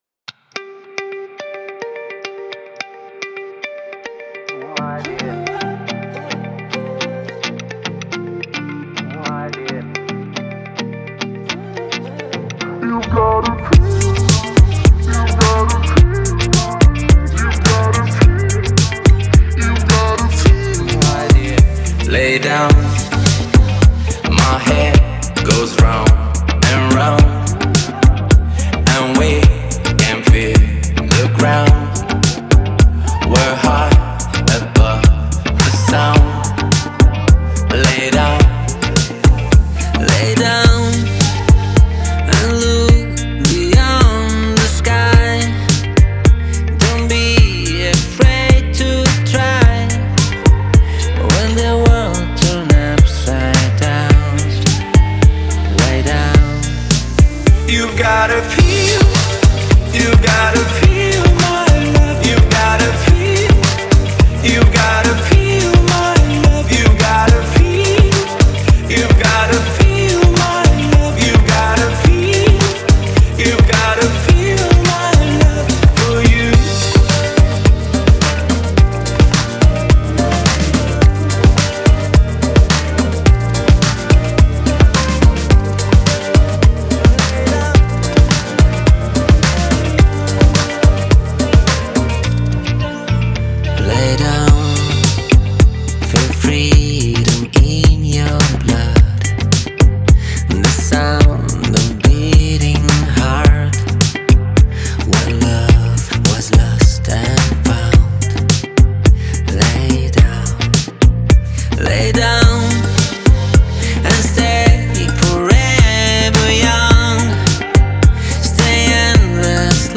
это энергичная поп-песня